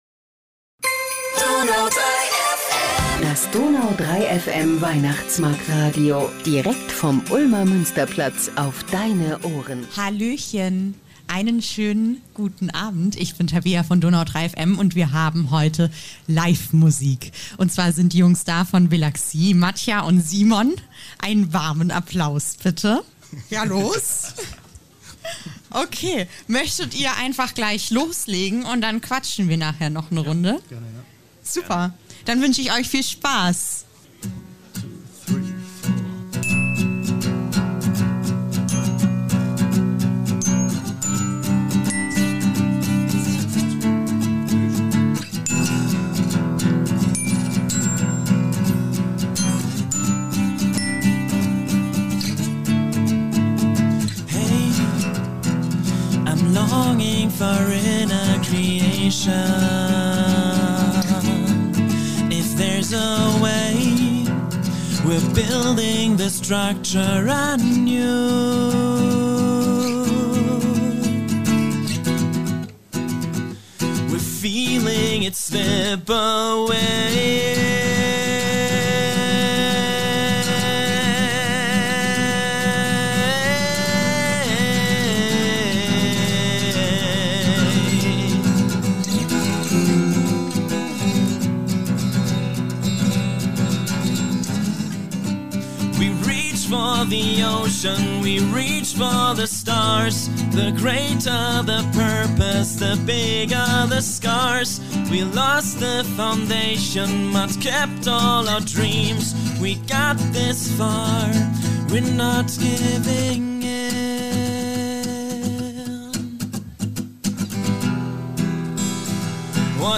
Heute waren im Weihnachtsmarktradio gleich zwei großartige Bands mit noch sympathischeren Musikern zu Besuch. Vom Tamburin-Guy bis zum Xylophon aus dem Kinderzimmer wurden alle Register gezogen - heraus kam dabei ein Abend mit jeder Menge Musik, Spaß und lustigen Gesprächen.